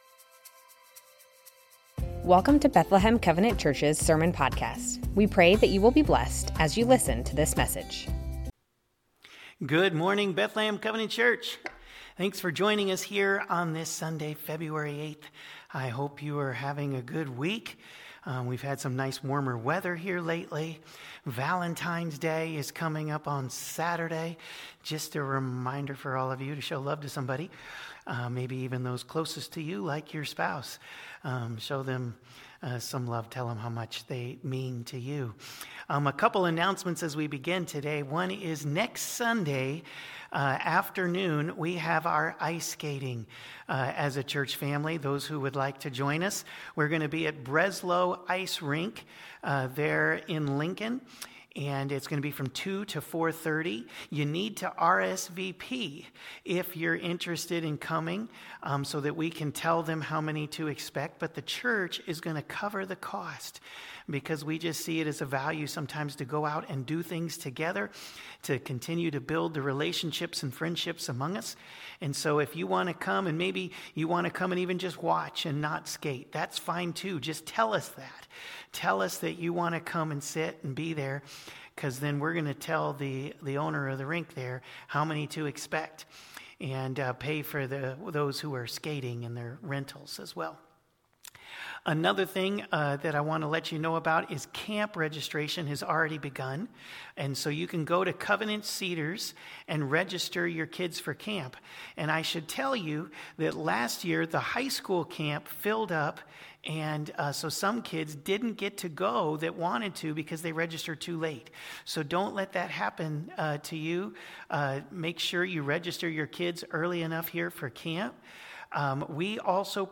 Bethlehem Covenant Church Sermons The Ten Commandments - Honor your mom & dad Feb 08 2026 | 00:39:26 1x 00:00 / 00:39:26 Subscribe Share Spotify RSS Feed Share Link Embed